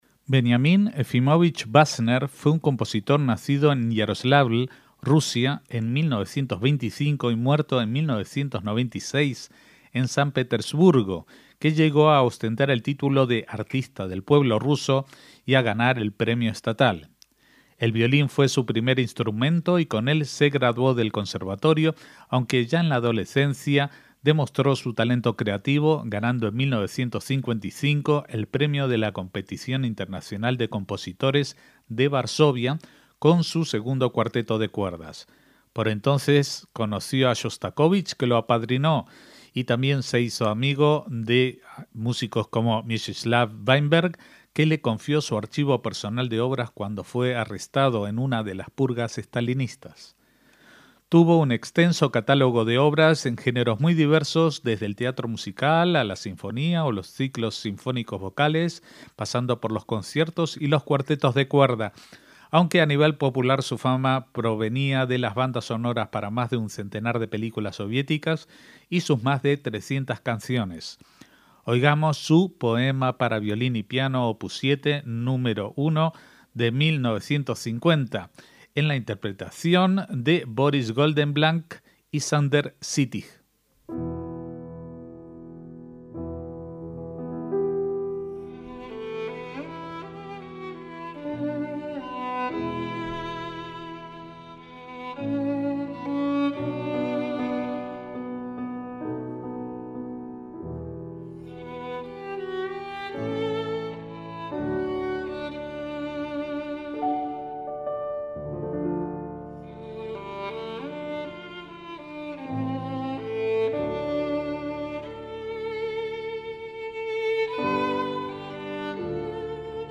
MÚSICA CLÁSICA - Veniamin Basner fue un compositor soviético nacido en 1925 y fallecido en 1996, reconocido como Artista del Pueblo de Rusia y ganador del premio estatal.